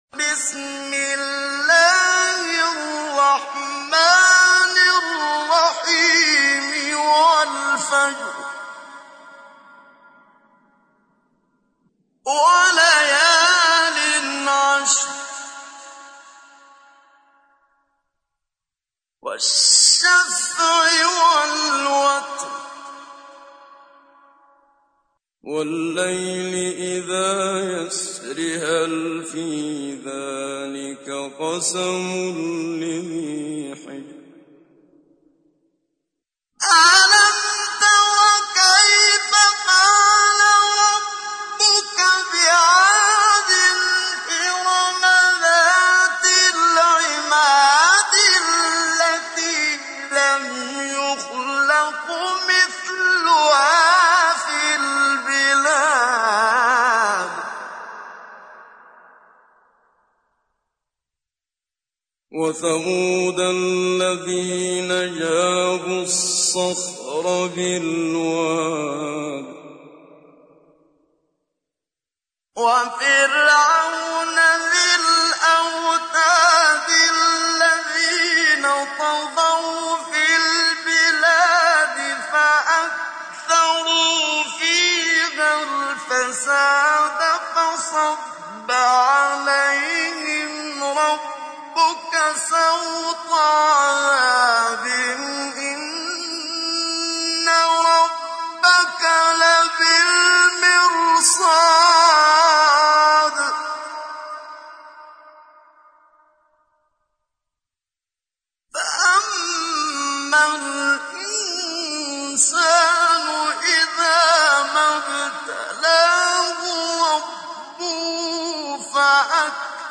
تحميل : 89. سورة الفجر / القارئ محمد صديق المنشاوي / القرآن الكريم / موقع يا حسين